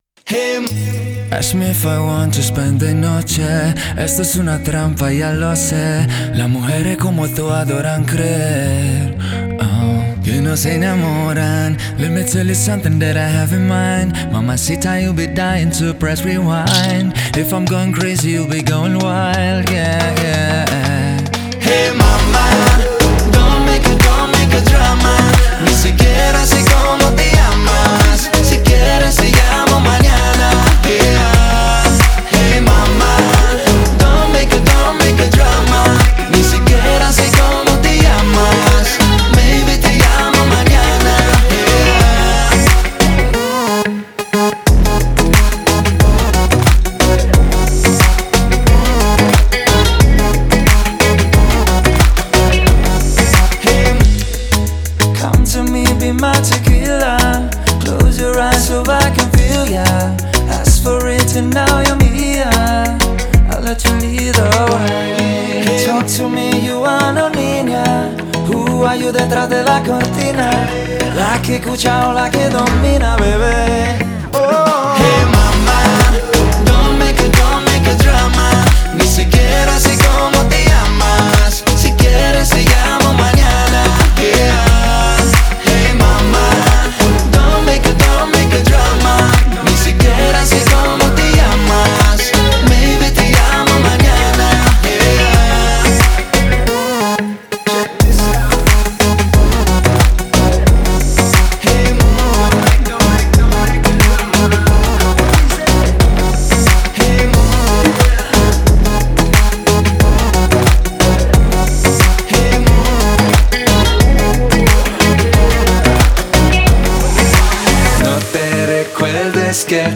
это энергичная трек в жанре EDM